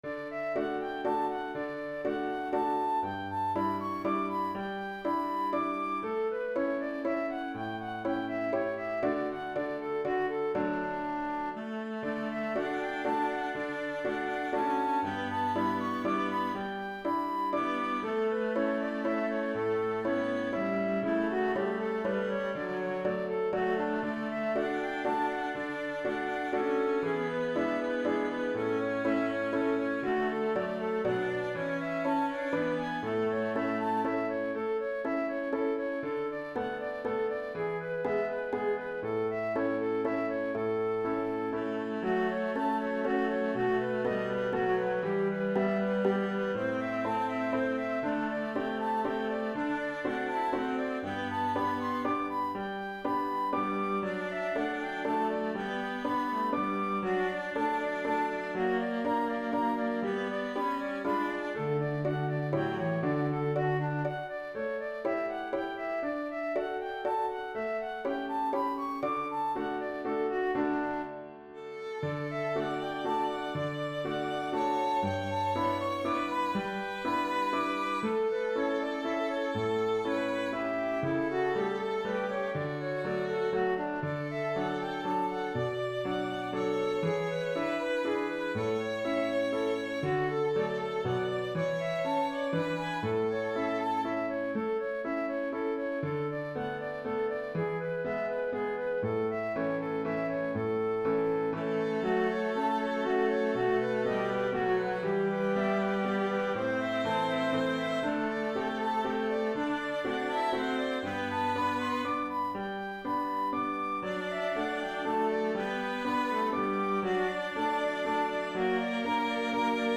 Canzonetta from Don Giovanni Mozart Flute, violin, cello, and piano
In the arrangement for flute, violin, cello, and piano, I take some of the voice part away from the cello and give it to the violin.
Throughout, I've retained Mozart's delightful harmonization; in addition to standard triads, we find some surprising 6th, 7th, and diminished chords.
canzonetta-F-Vln-Vc-Pf.mp3